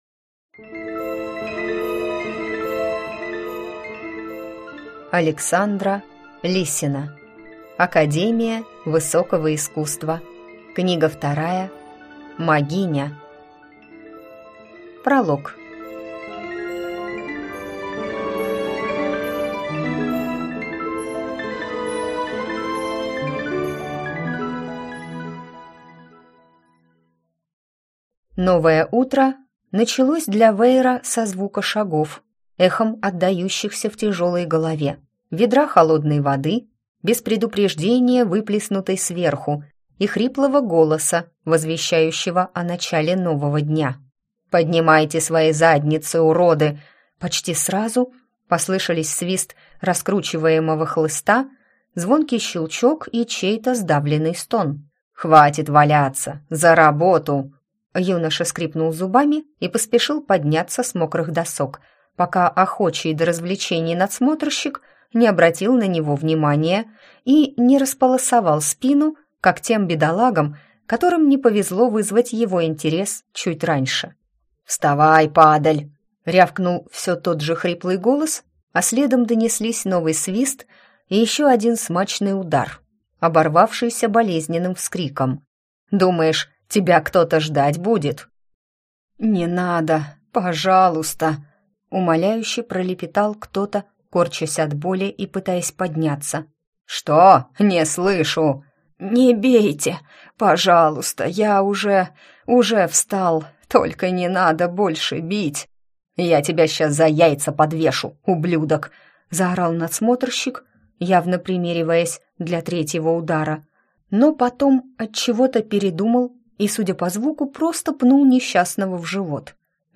Аудиокнига Магиня | Библиотека аудиокниг